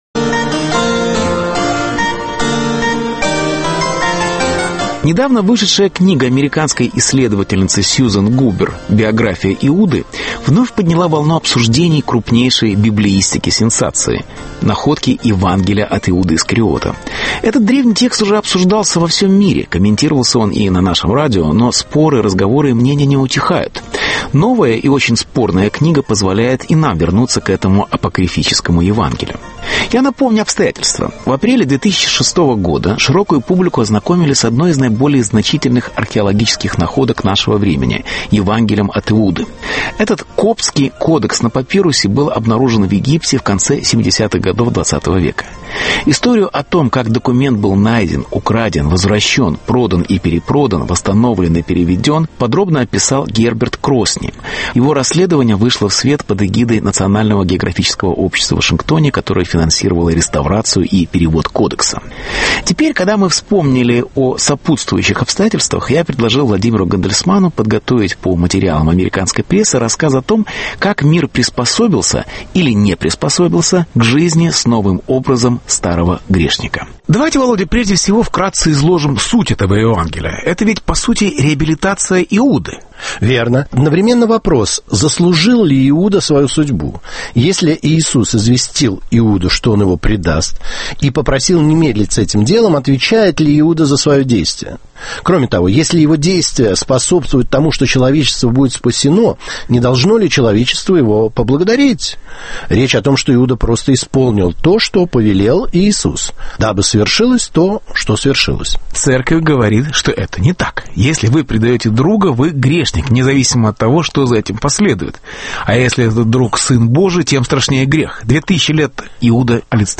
«Евангелие от Иуды»: пределы переоценки. Беседа